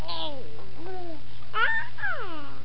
Baby Sound Sound Effect
Download a high-quality baby sound sound effect.
baby-sound.mp3